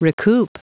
Prononciation du mot recoup en anglais (fichier audio)
Prononciation du mot : recoup